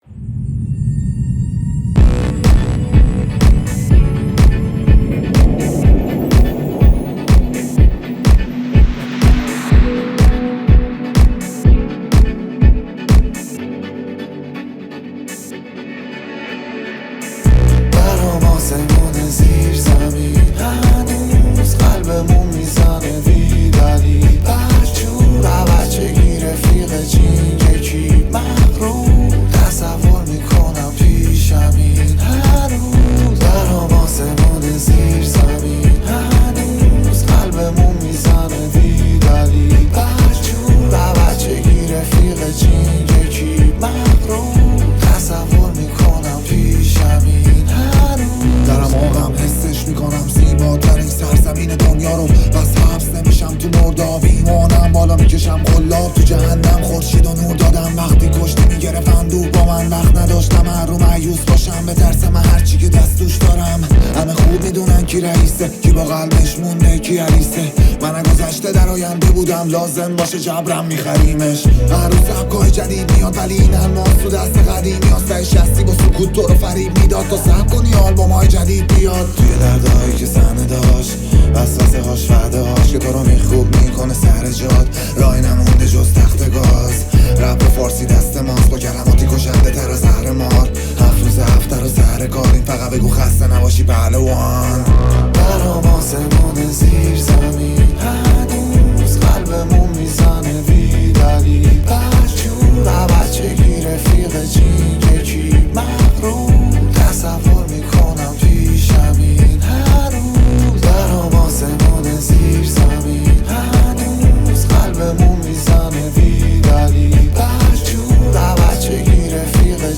شاد و پرانرژی